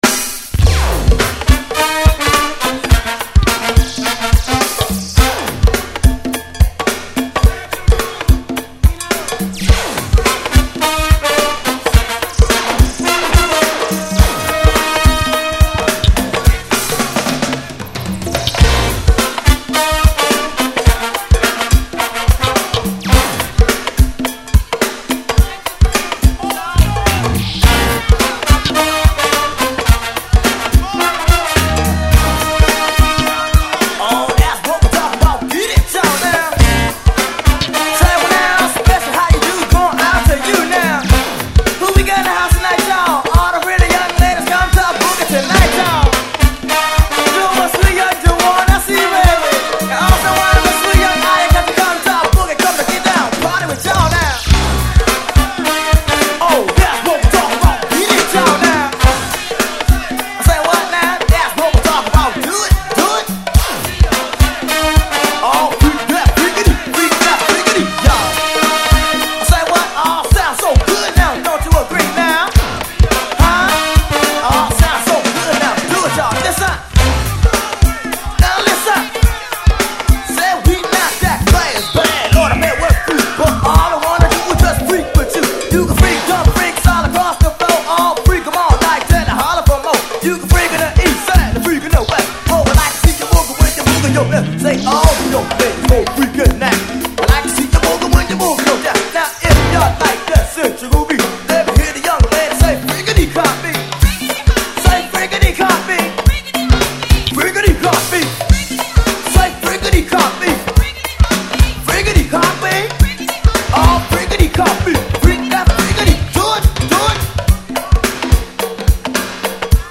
・ DISCO 70's 12'
Old School〜Disco Rapと相性の良いマイナーGo-Go！